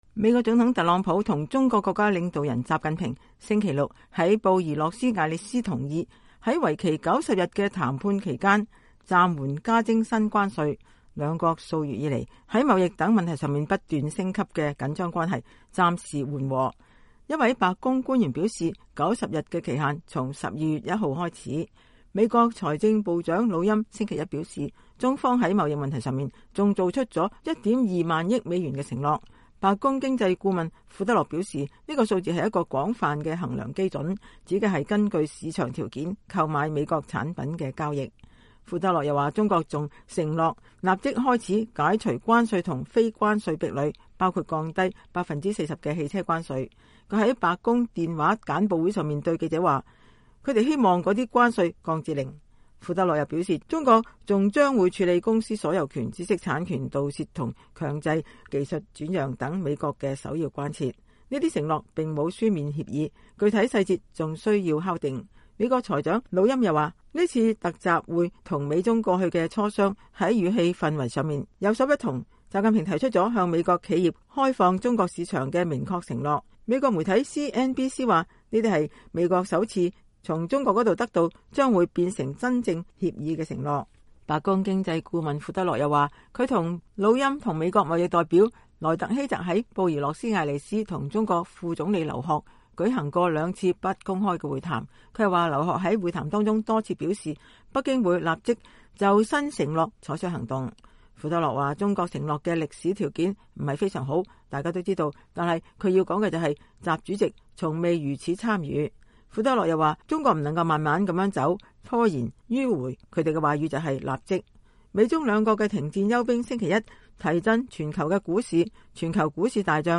白宮首席經濟顧問庫德洛2018年12月3日在白宮對記者談論對華貿易談判。